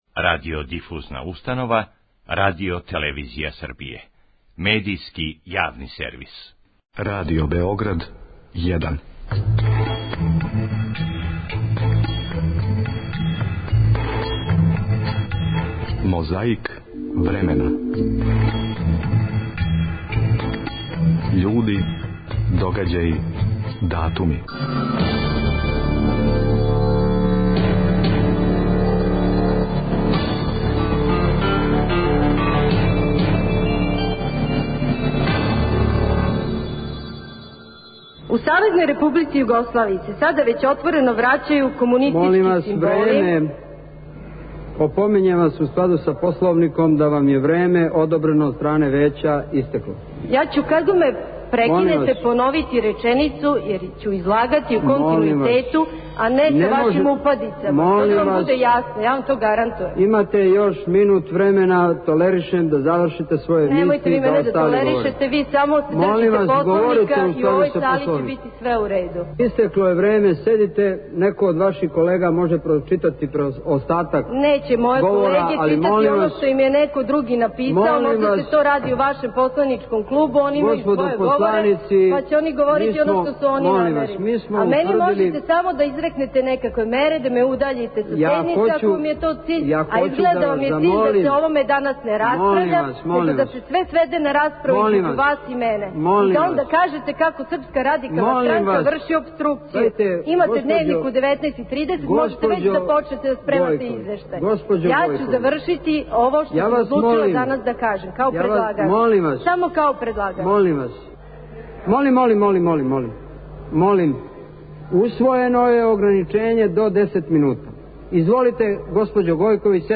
Овонедељну борбу против пилећег памћења започињемо инсертом из скупштинског заседања којим је председавао Радоман Божовић, а реч је имала народна посланица Маја Гојковић.